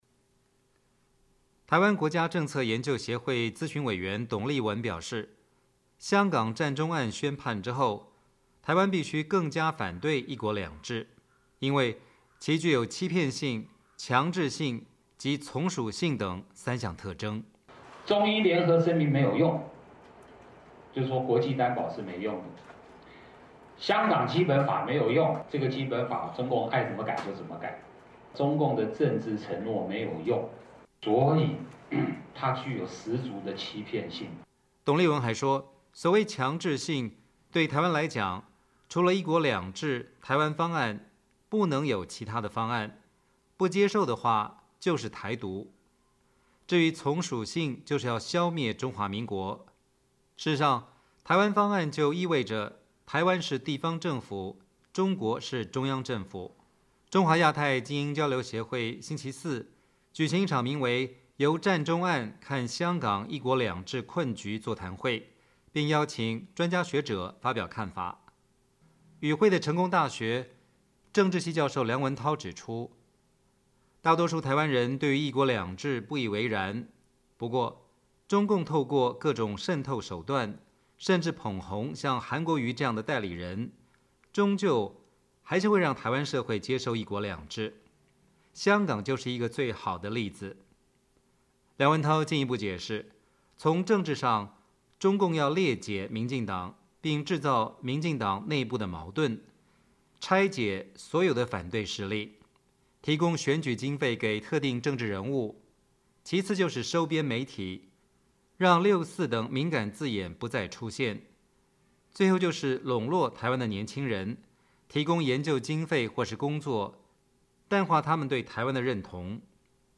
中华亚太精英交流协会星期四(4月25号)举行一场名为“由占中案看香港一国两制困局”座谈会，并邀请专家学者发表看法。